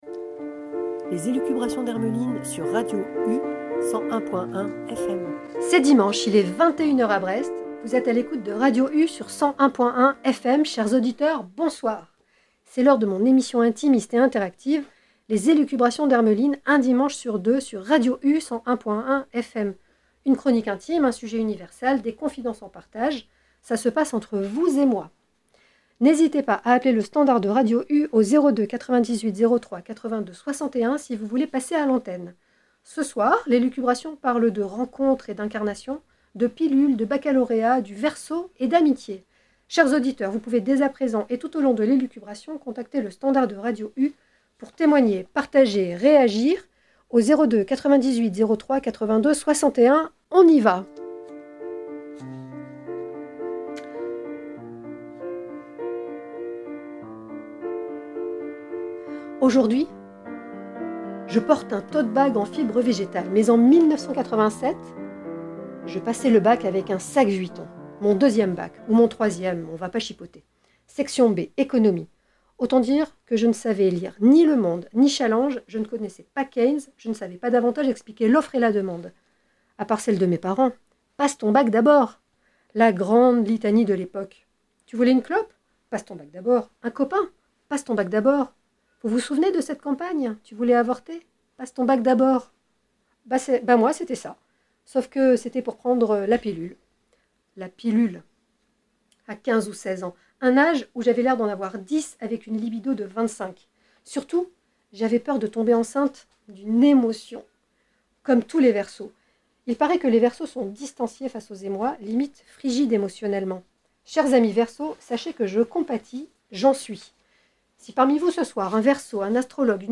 Jingle intro & outro